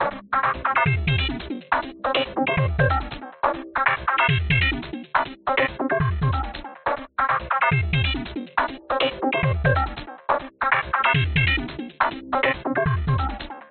描述：一些合成器的声音+FM效果。
Tag: 电子 电子 循环 合成器